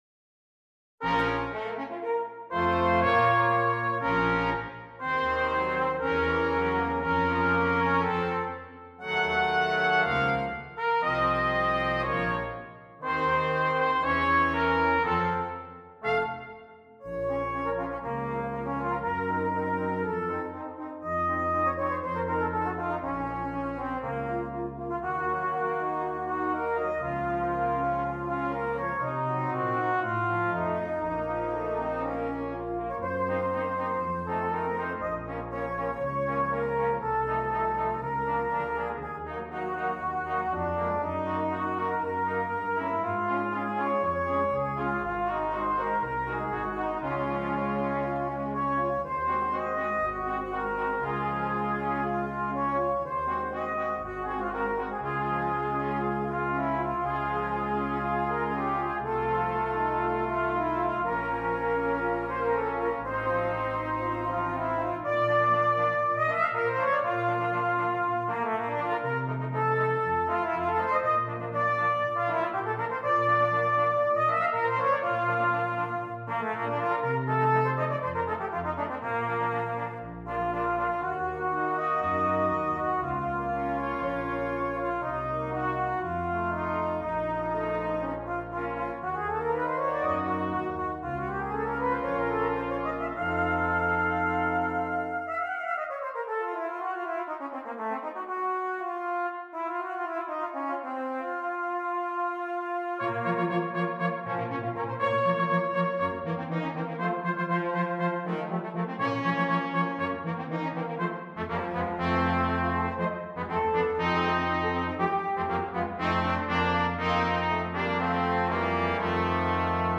Brass Quintet
This version for quintet adds a great depth of sound.